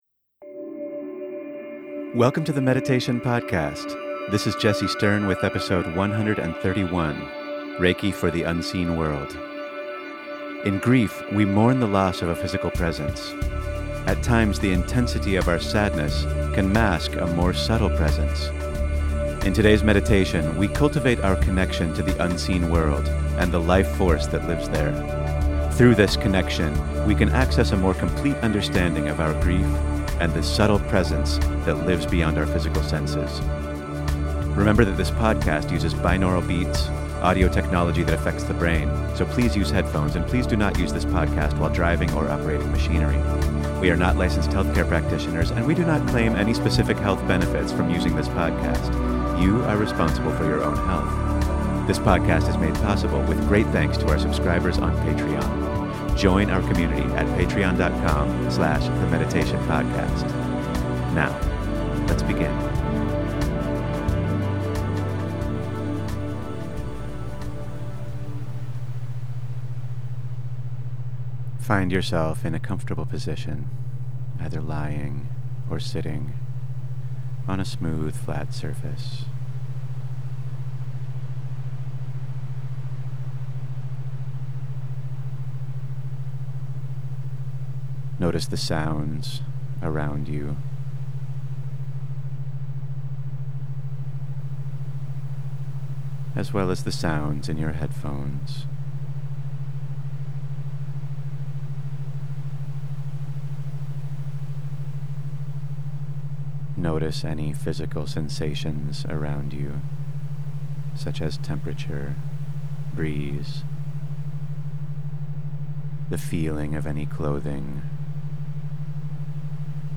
In today's meditation, we cultivate our connection to the unseen world and the Life Force that lives there. Through this connection, we can access a more complete understanding of our grief, and the subtle presence that lives beyond our physical senses.